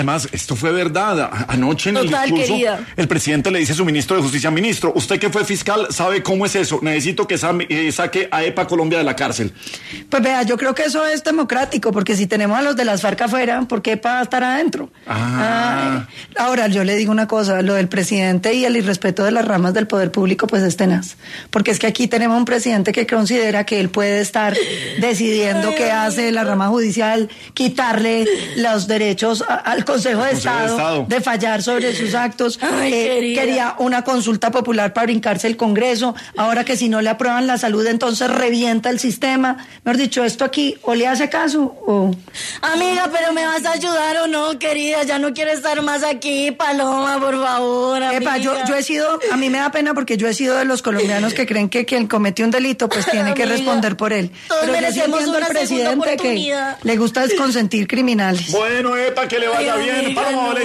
En su paso por los micrófonos de la Luciérnaga y ‘Sin Anestesia’ de Caracol Radio, la precandidata presidencial, Paloma Valencia, habló acerca de la reciente solicitud que realizó el presidente Gustavo Petro al ministro de justicia, Eduardo Montealegre, durante el Consejo de Ministros, pidiéndole realizar la diligencia para que la reconocida influencer ‘Epa Colombia’ salga de la cárcel.